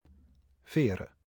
Veere (Dutch: [ˈveːrə]